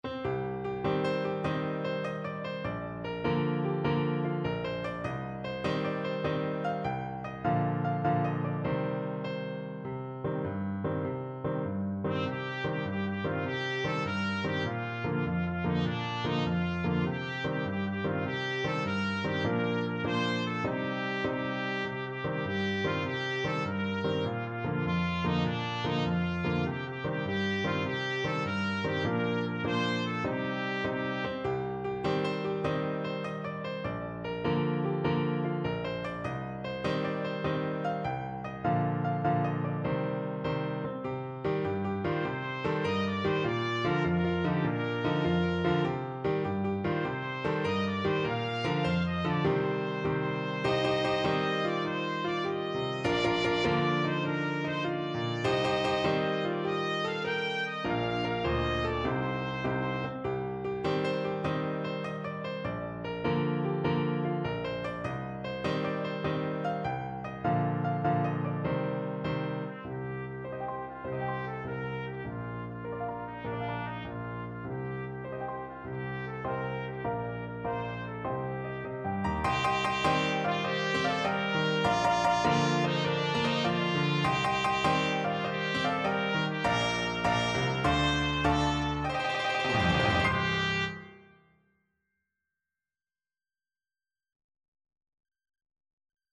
Trumpet
6/8 (View more 6/8 Music)
Allegro .=c.100 (View more music marked Allegro)
C5-G6
G minor (Sounding Pitch) A minor (Trumpet in Bb) (View more G minor Music for Trumpet )
Traditional (View more Traditional Trumpet Music)